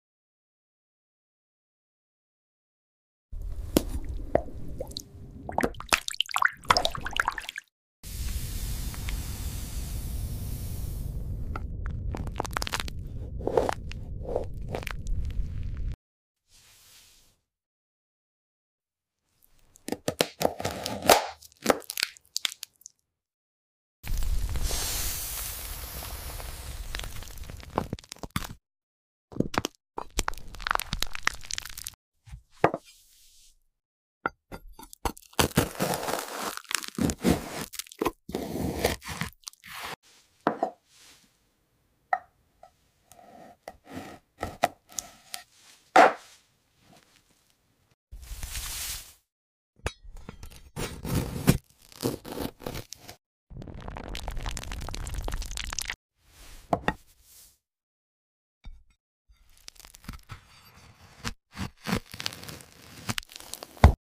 Satisfying ASMR Sounds 🔪💥 Listen to the crack, squish, and crunch as we slice open mysterious dragon eggs!